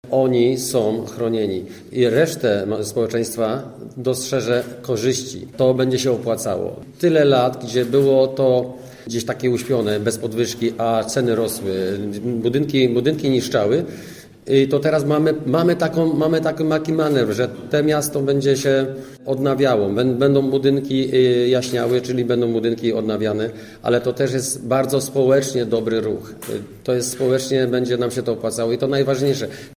-Dla mnie ważne jest to, że chronione są rodziny i osoby w złej sytuacji materialnej – mówi radny klubu Gorzów Plus Tomasz Manikowski: